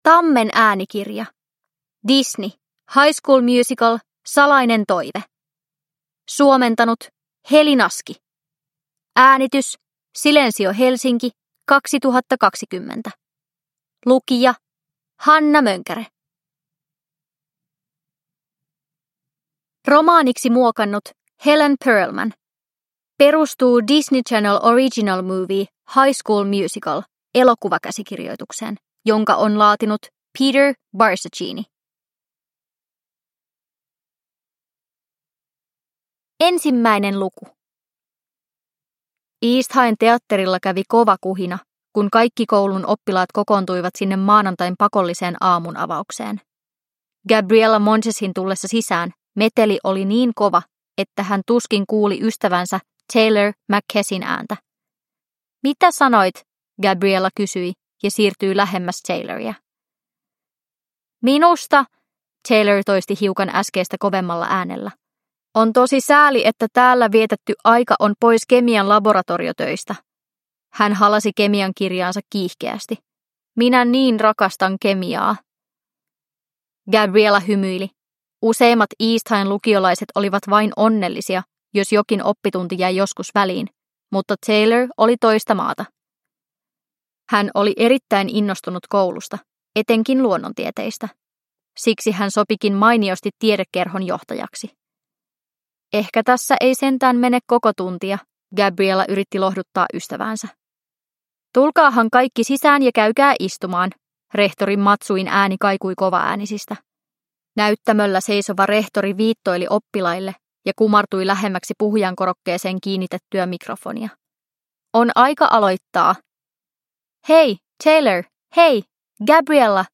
High School Musical. Salainen toive – Ljudbok – Laddas ner